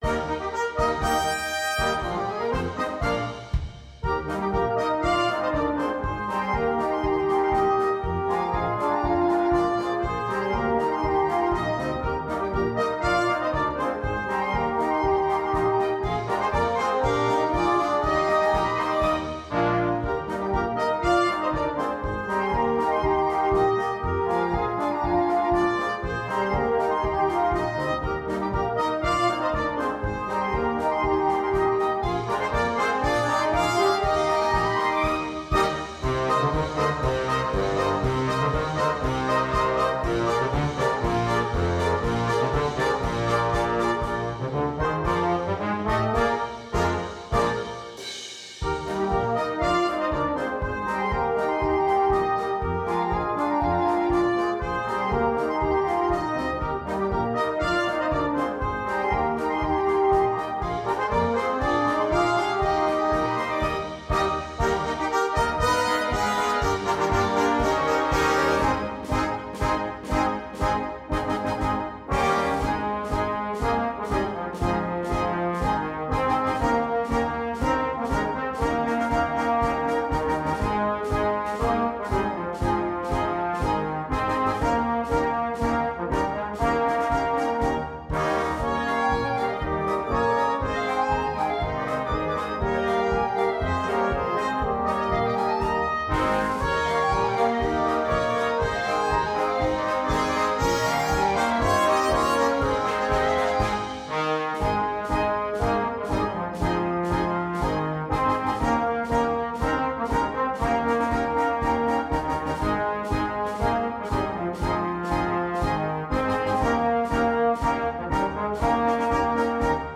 2. Blaskapelle
Marsch